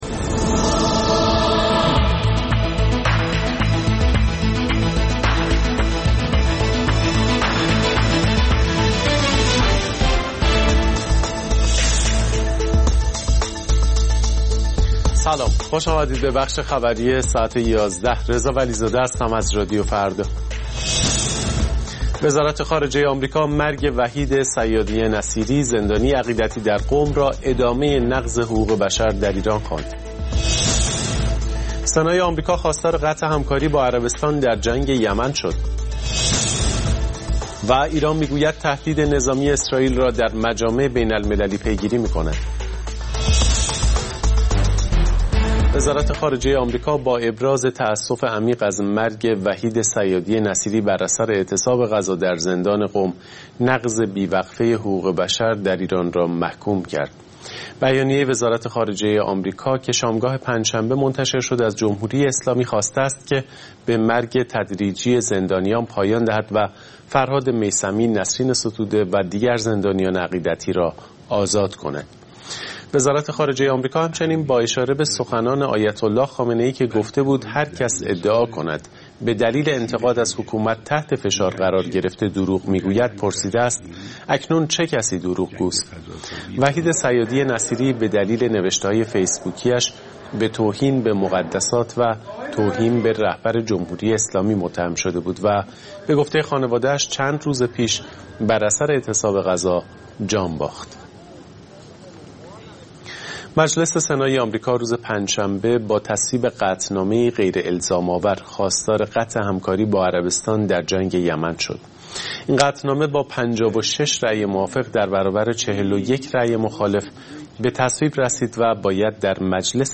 اخبار رادیو فردا، ساعت ۱۱:۰۰